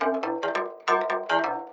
11 Harsh Realm Guitar Noise Riff.wav